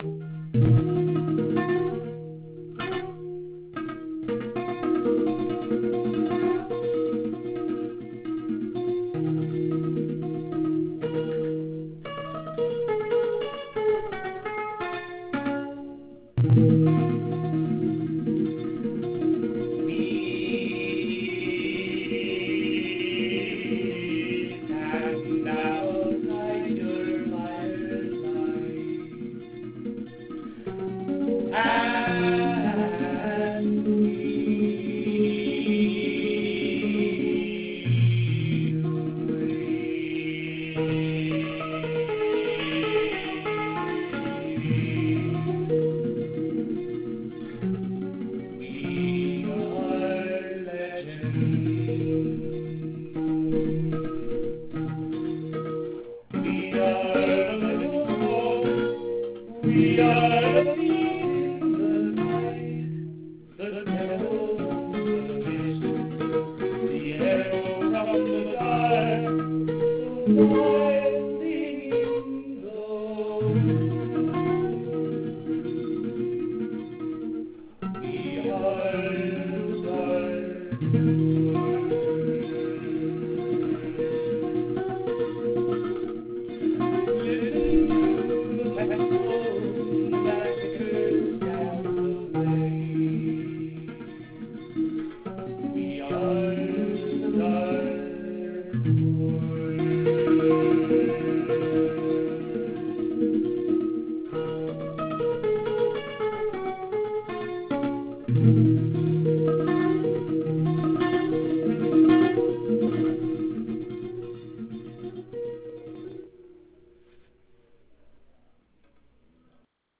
Click Here For Sung Version ( Real Audio )